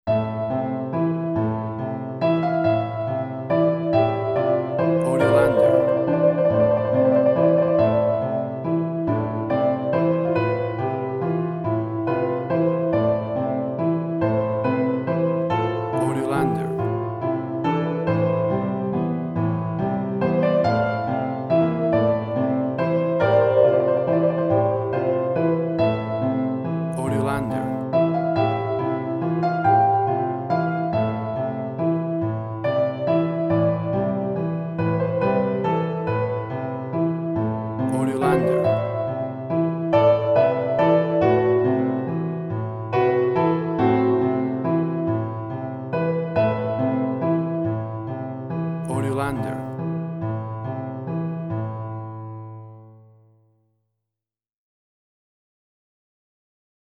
Indie Quirky.
Tempo (BPM): 47